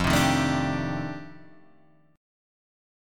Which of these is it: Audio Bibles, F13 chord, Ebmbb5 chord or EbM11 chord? F13 chord